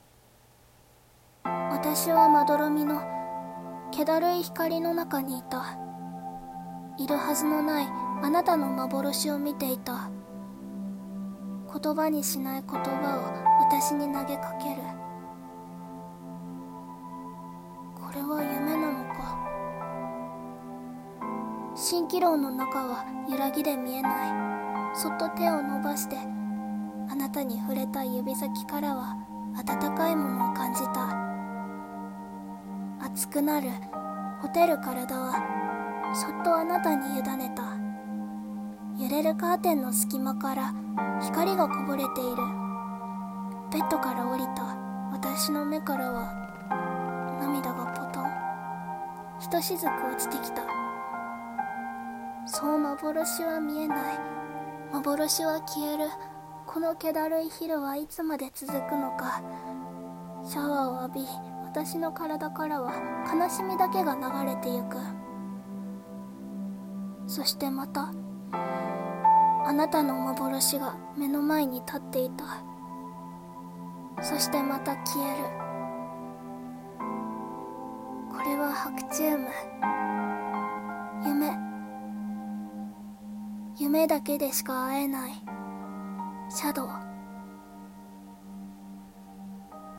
白昼夢~一人声劇